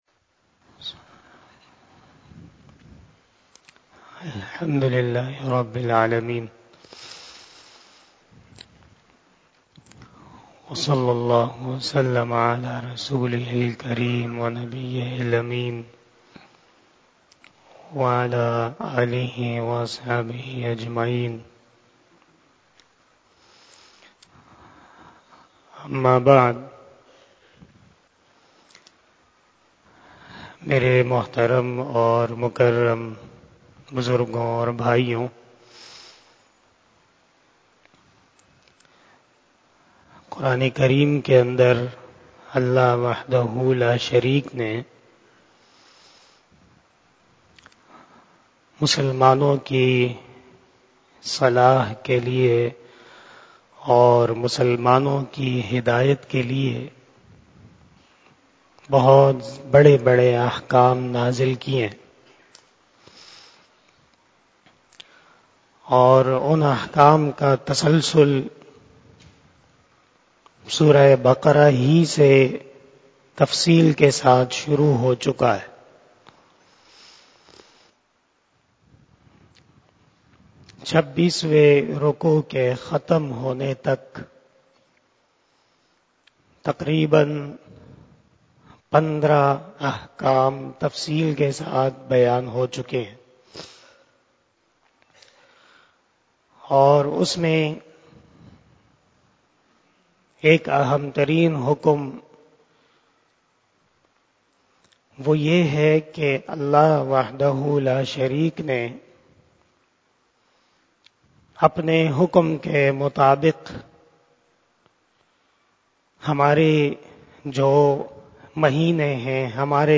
08 BAYAN E JUMMA 24 February 2023 (03 Shaban 1444H)
03:02 PM 313 Khitab-e-Jummah 2023 --